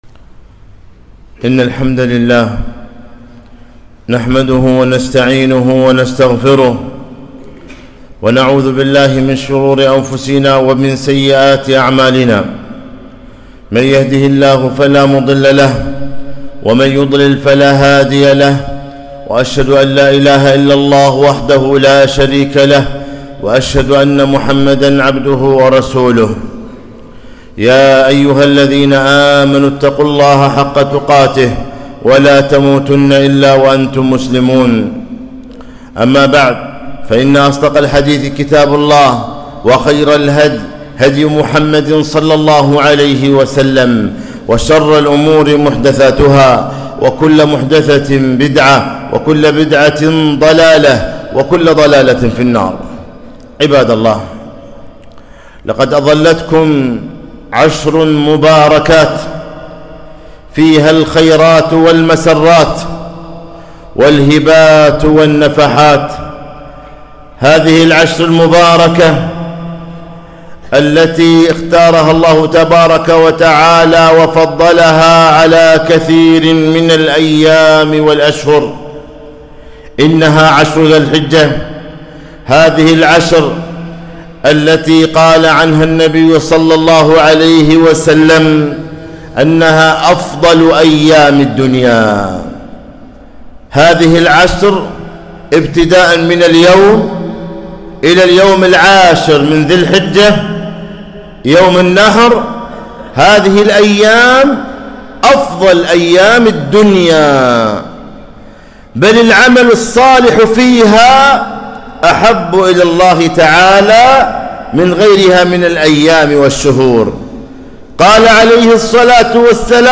خطبة - أظلتكم عشر ذي الحجة فهل من مشمِّر